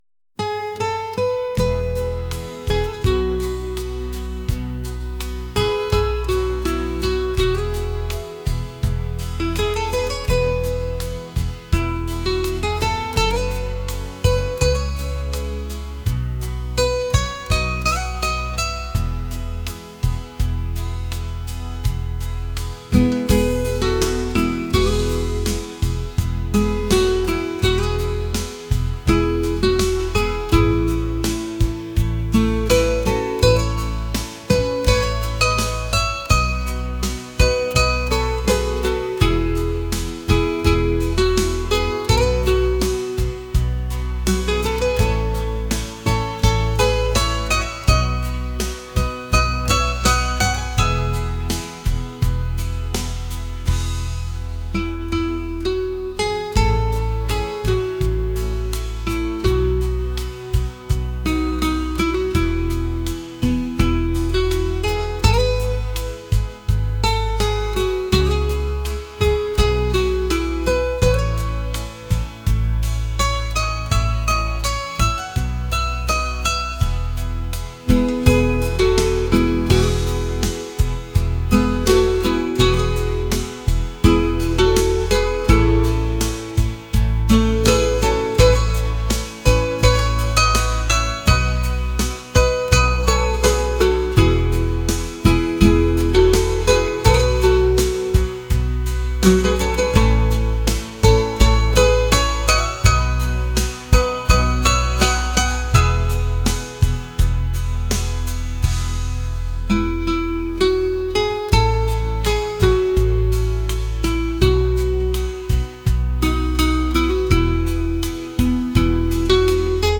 pop | acoustic | ambient